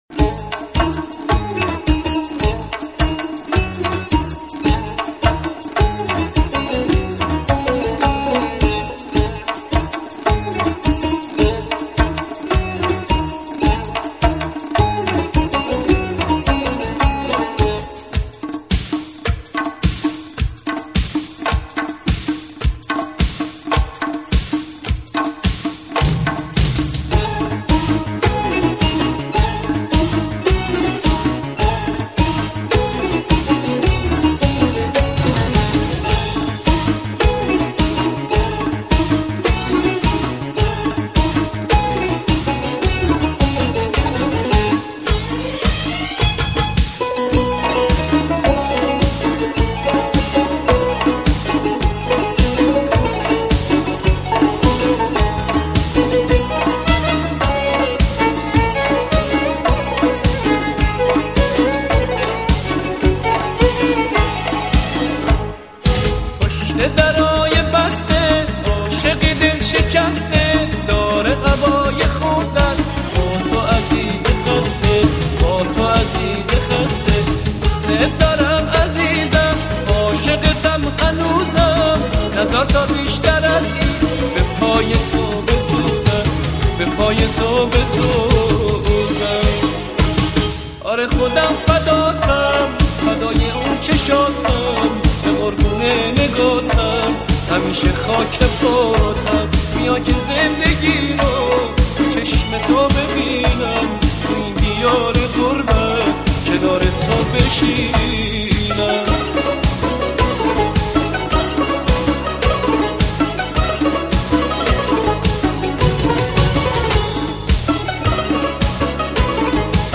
آهنگ ایرانی رقص عروس داماد